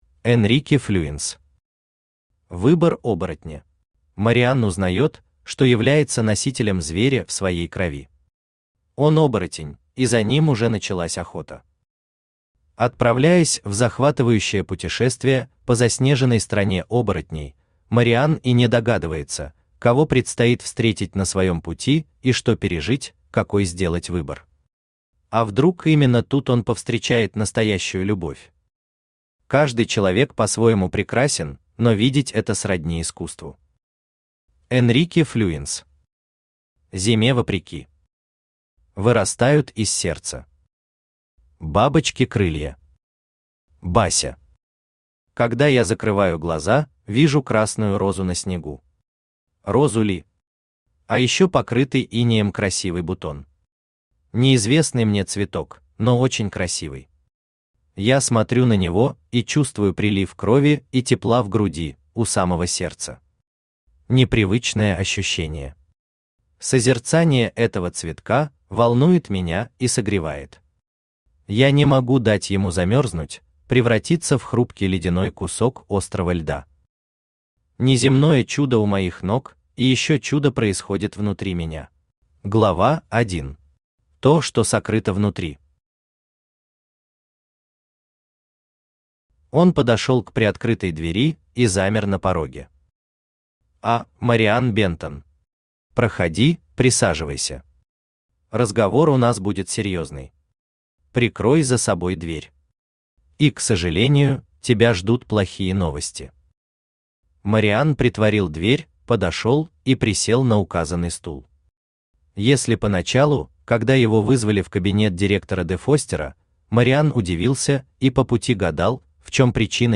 Аудиокнига Выбор оборотня | Библиотека аудиокниг
Aудиокнига Выбор оборотня Автор Энрике Флюенс Читает аудиокнигу Авточтец ЛитРес.